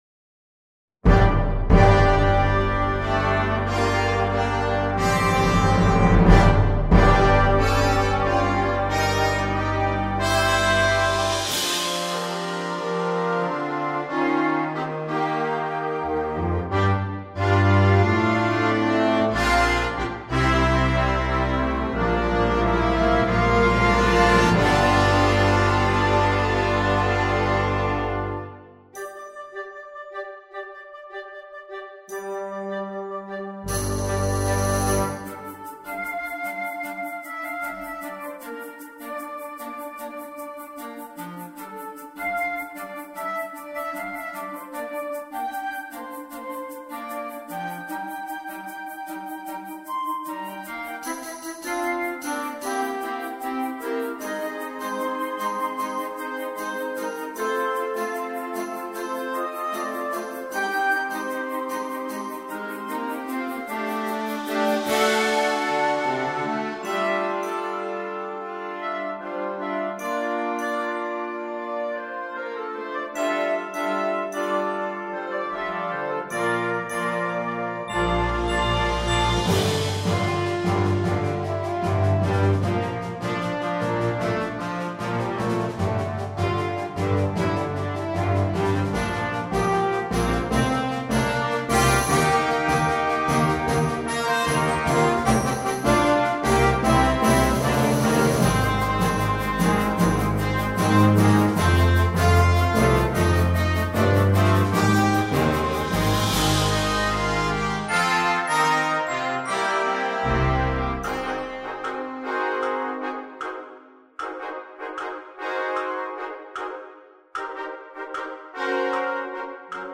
for wind band